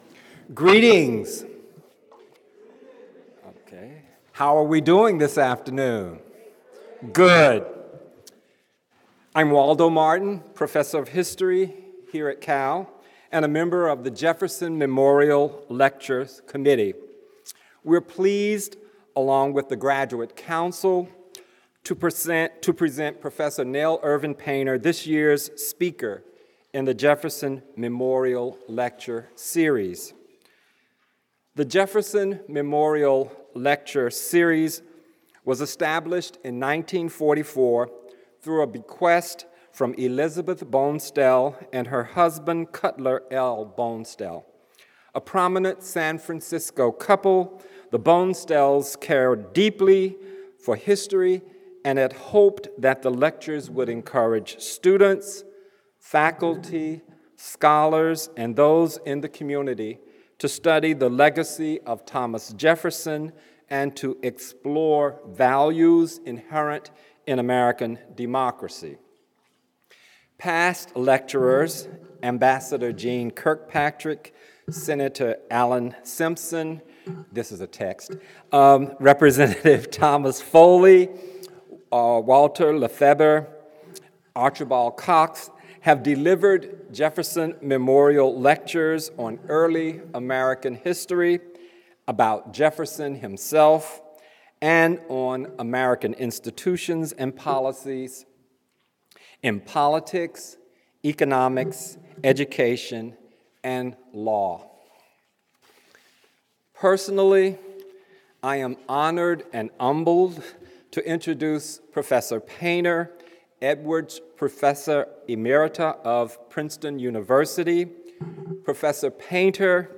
Edwards Professor of American History, Emerita, Princeton University
Nell Painter’s lecture will combine the discursive meanings of scholarship with the visual meaning of painting, to answer, literally, why white people are called ‘Caucasian,’ what that looks like, and how they all relate to our ideas about personal beauty.